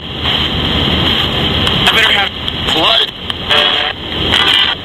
PrivateGB#9 -  After the scan had stopped for a bit and restarted,  a voice says "I'm going to have....blood."